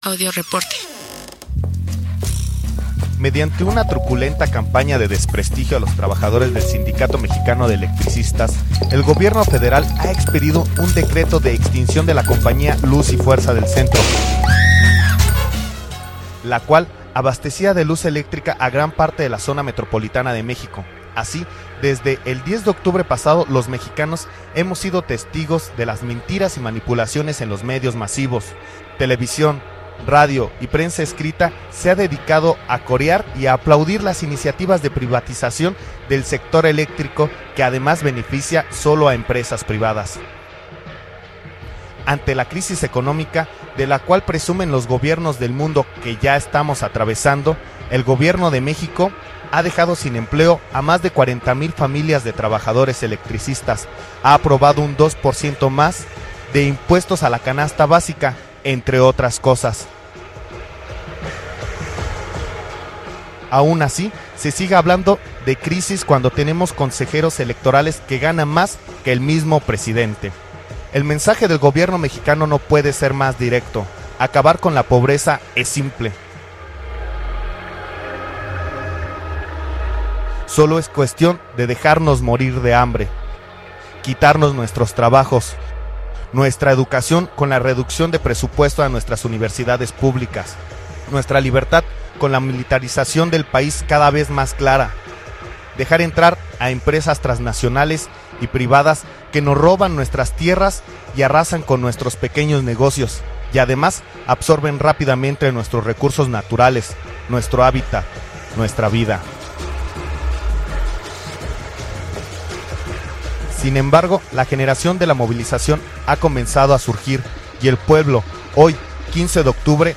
56_Audioreporte_SME.mp3